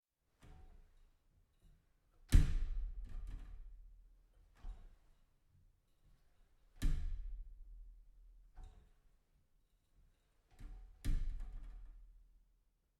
Doors
Glass Shower Door
glass_shower_door.ogg